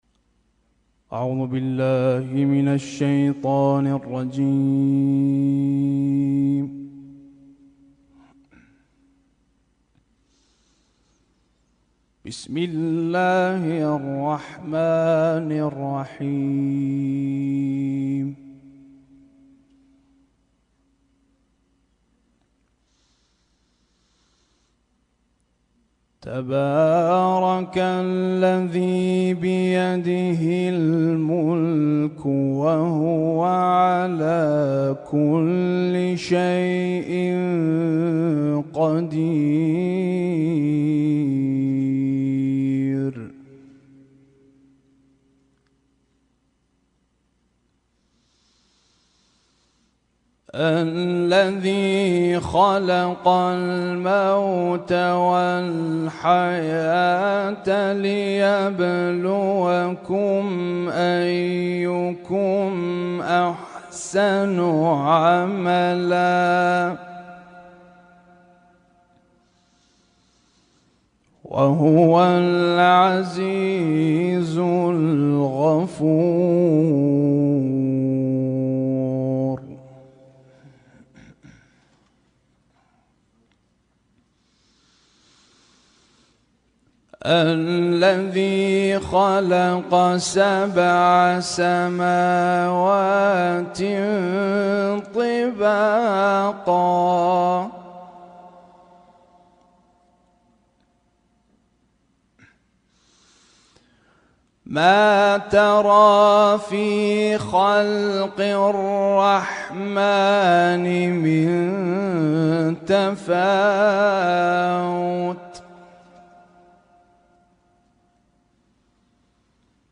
اسم التصنيف: المـكتبة الصــوتيه >> القرآن الكريم >> القرآن الكريم - القراءات المتنوعة